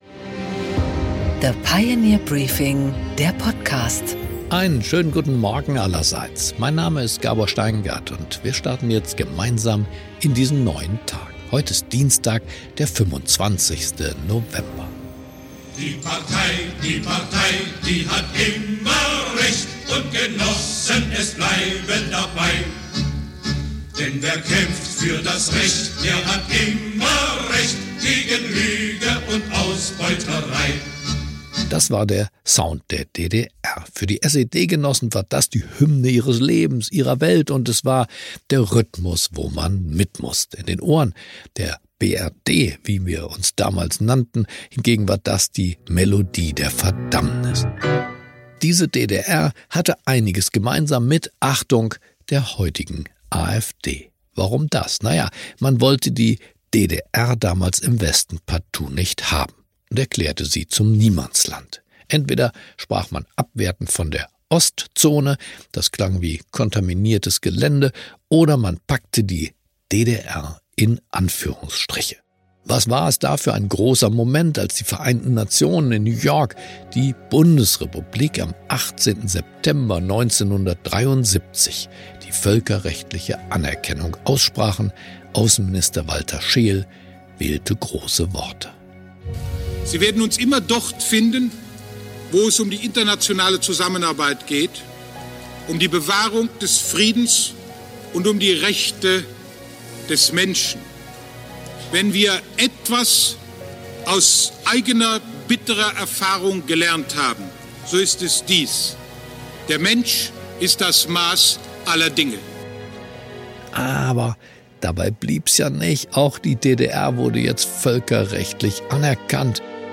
Gabor Steingart präsentiert das Pioneer Briefing.